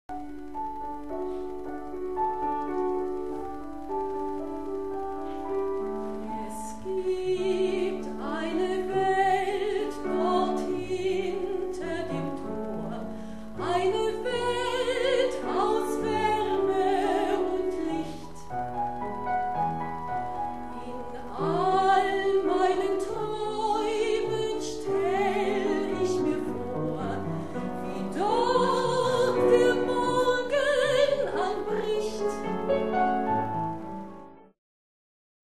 3-Personen-Musical mit Klavierbegleitung